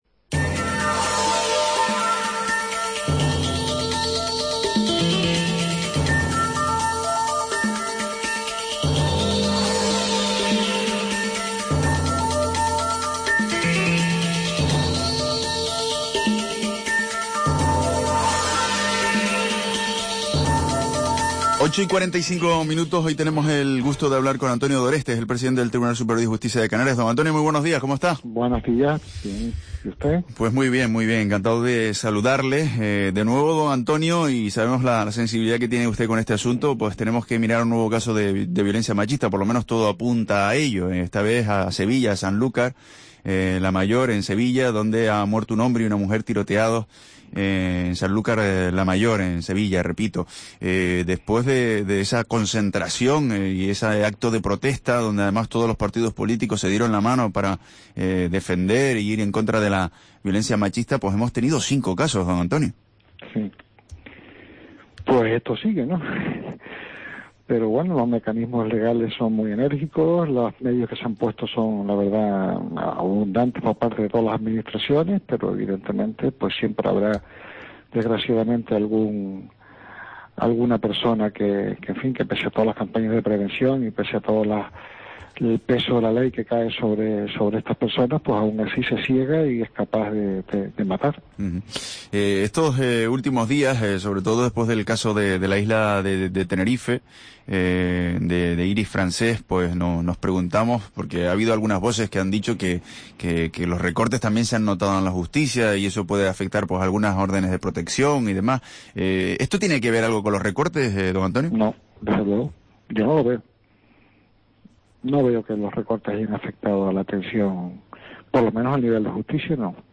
ENTREVISTA A ANTONIO DORESTE PTE. TSJC